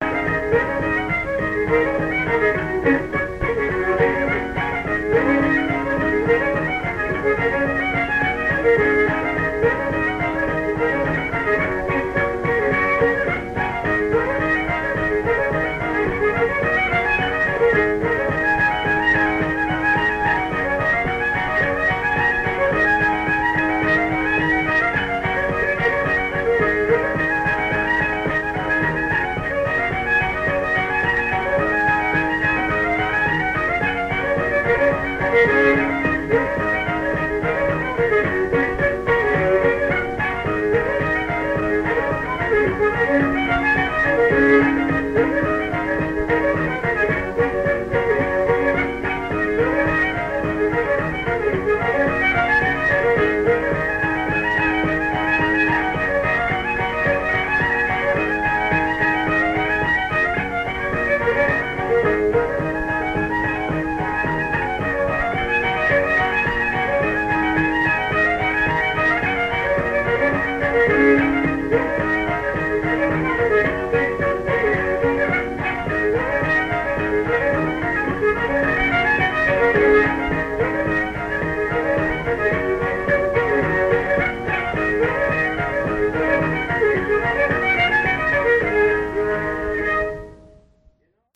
home recording) - Pigeon on the Gatepost
gtr